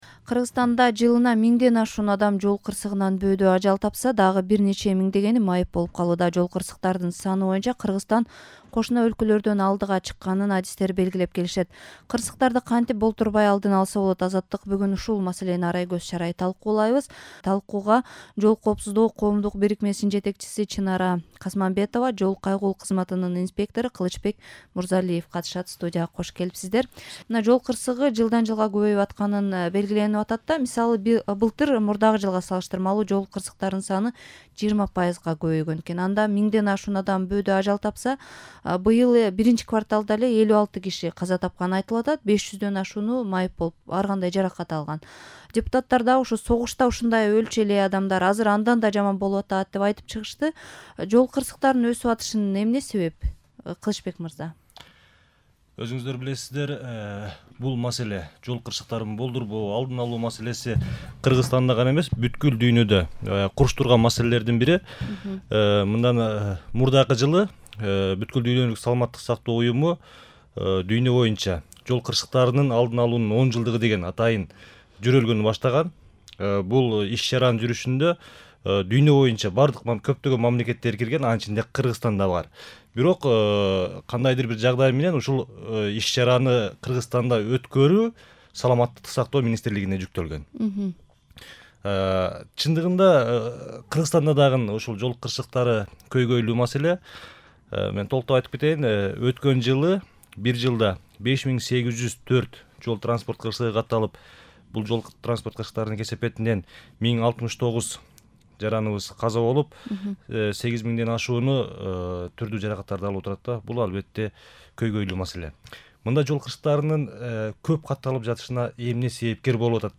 Жол коопсуздугу тууралуу талкуу (1)